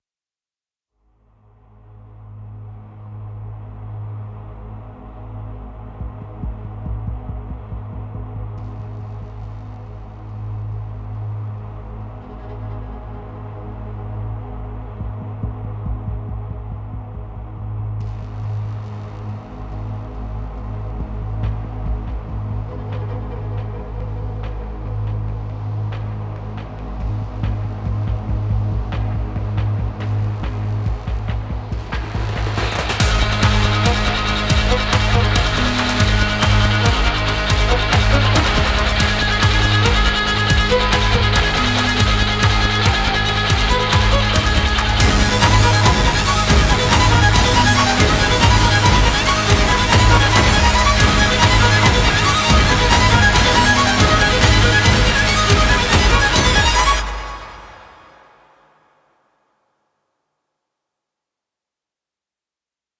———————————————— Production Music Examples ————————————————